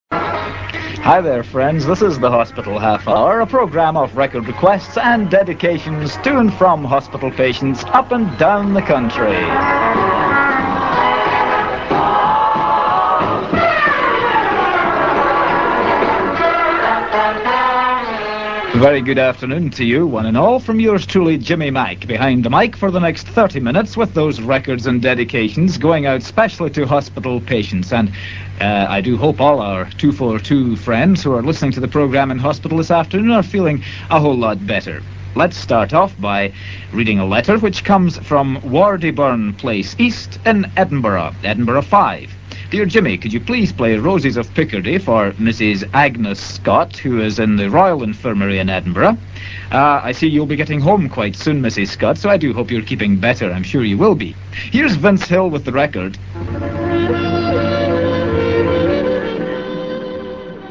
This clip is taken from a documentary about the offshore Radio Scotland made by the BBC station of the same name.